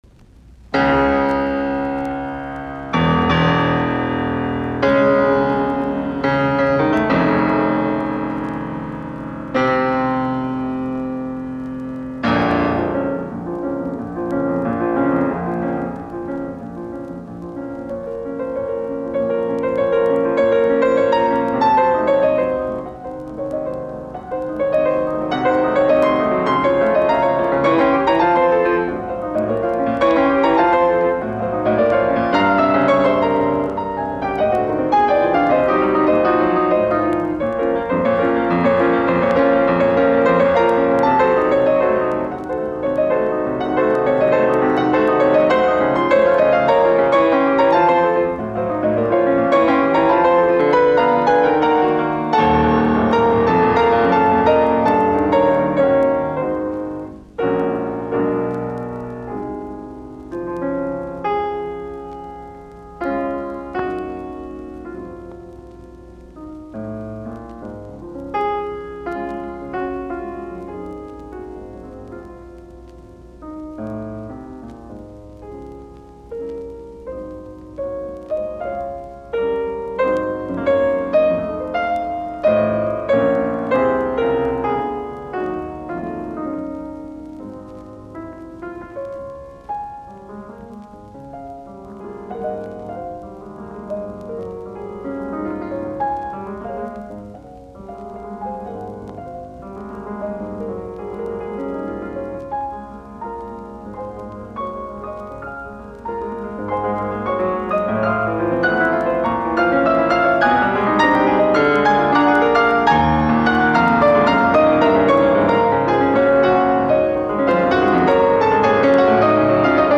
Sonaatit, piano, op35, b-molli
musiikkiäänite